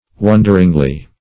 Wonderingly \Won"der*ing*ly\, adv.